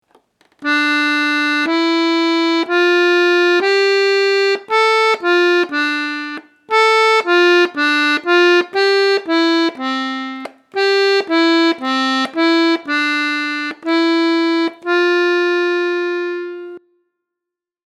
Ditado melódico-rítmico